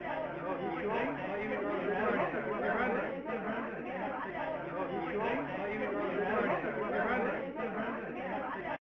Japanese Intro chatter from Super Mario All-Stars